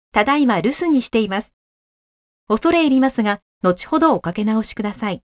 【アナウンスサービス　メッセージ一覧】
■アナウンスサービス３